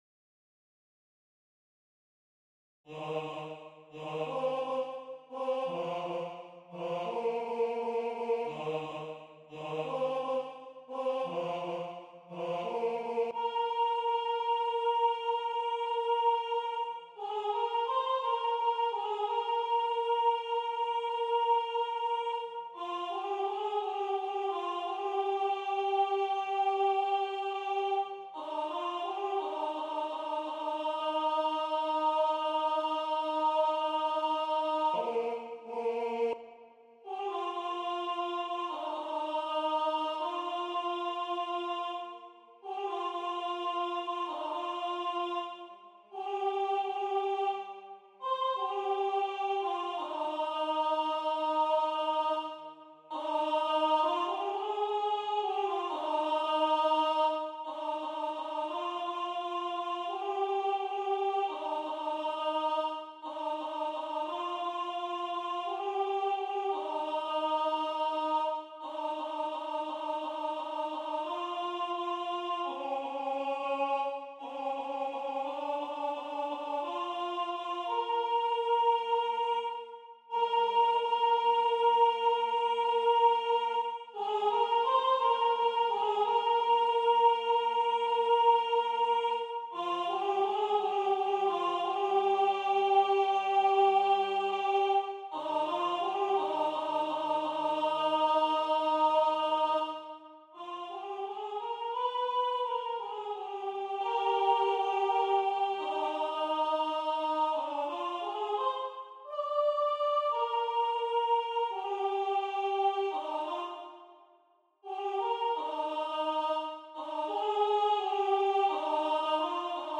alten - computerstem